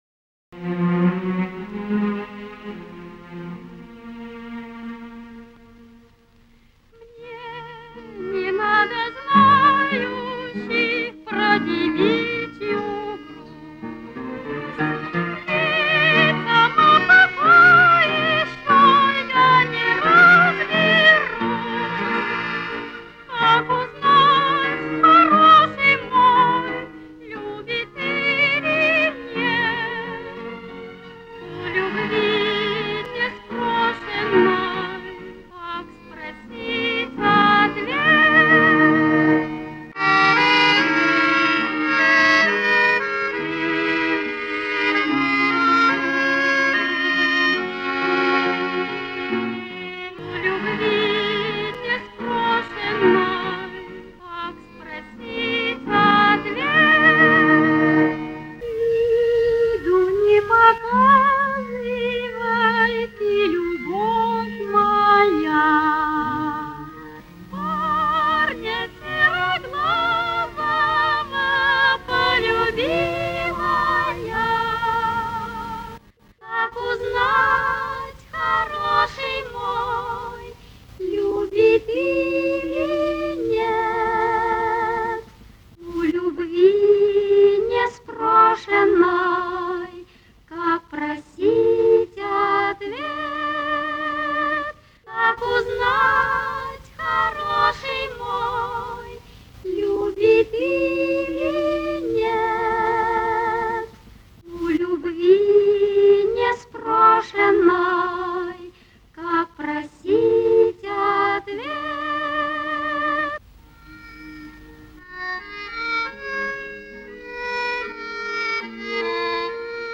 монтаж со звуковой дорожки фильма.